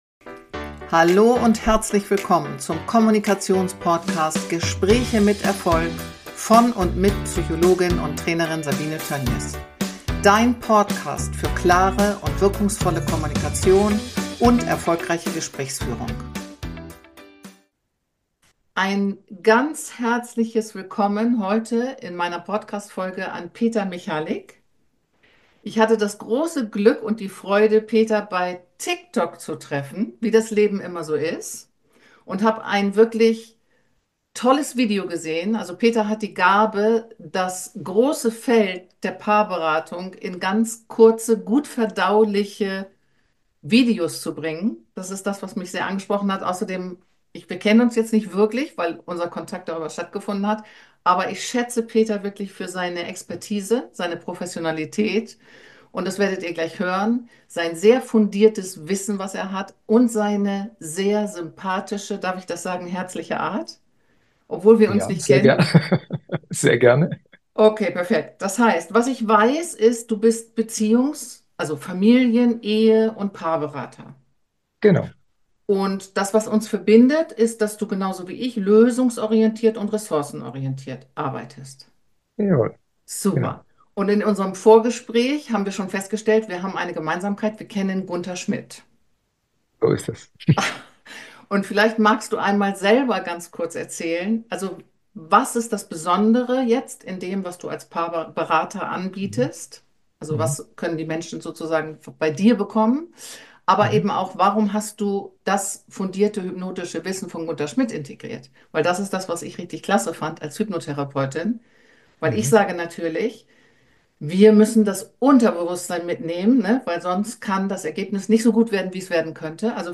Im Interview mit Beziehungscoach